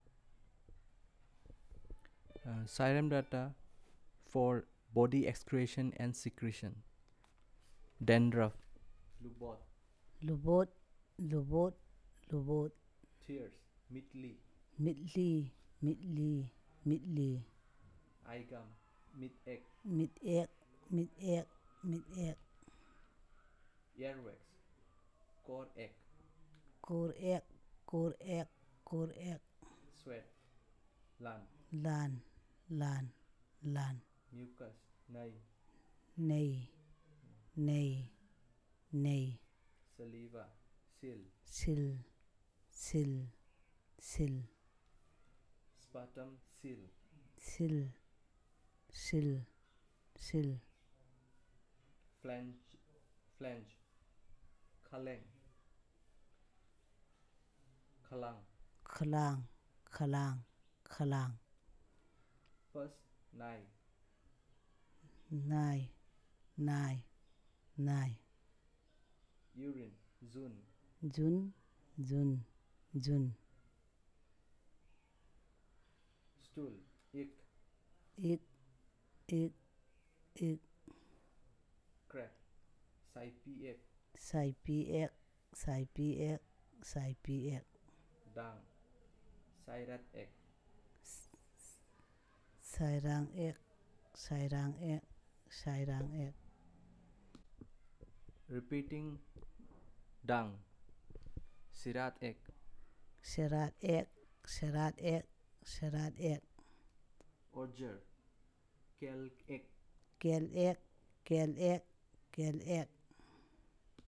Elicitation of words about body excretions and secretions